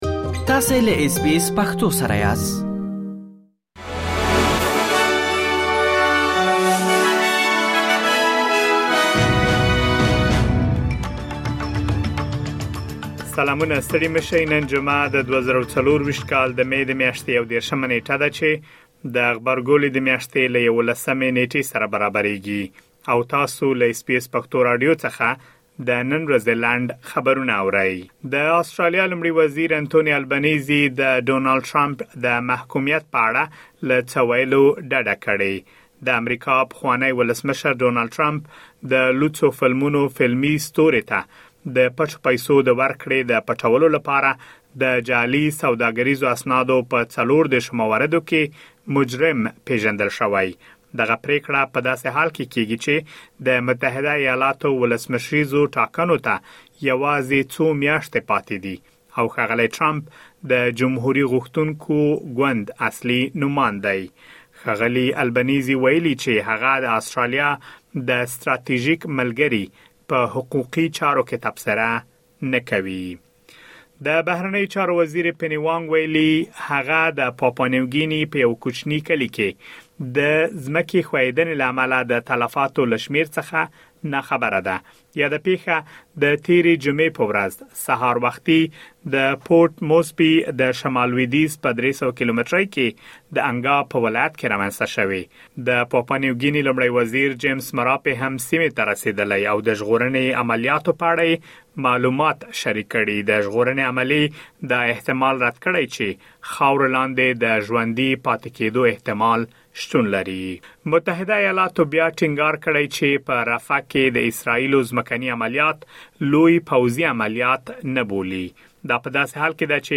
د اس بي اس پښتو د نن ورځې لنډ خبرونه|۳۱ مې ۲۰۲۴